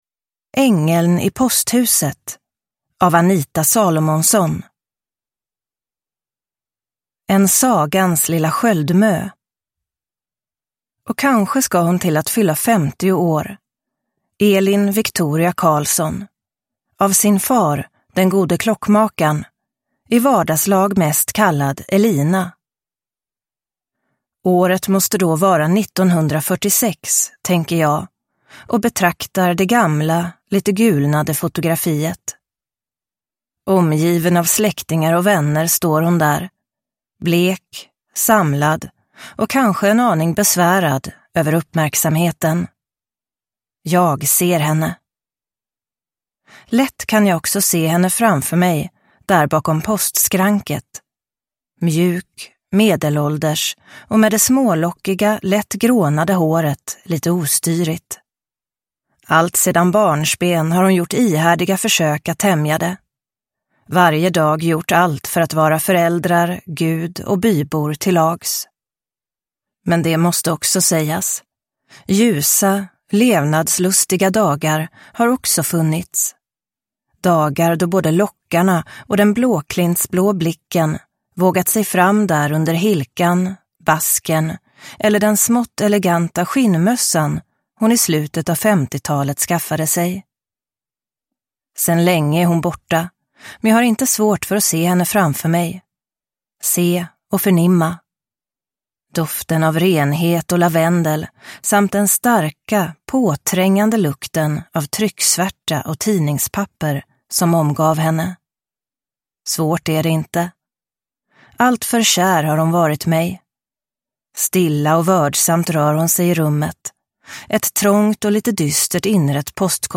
Ängeln i posthuset – Ljudbok – Laddas ner